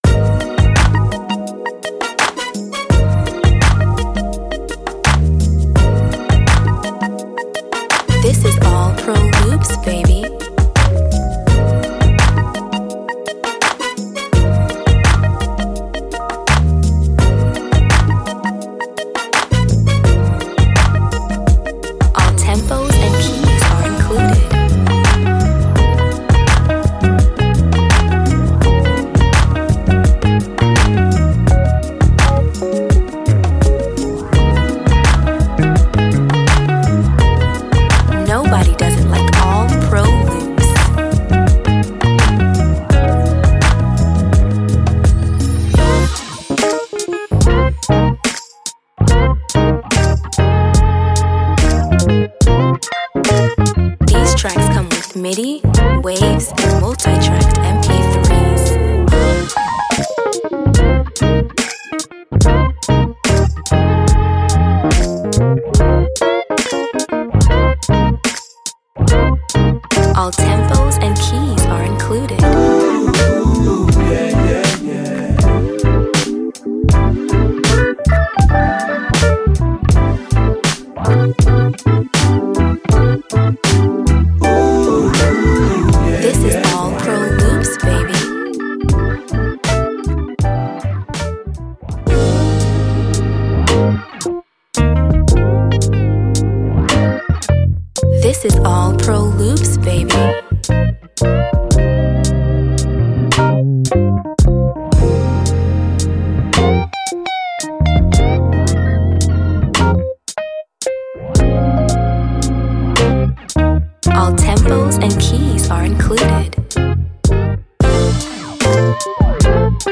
feel good Neo Soul tunes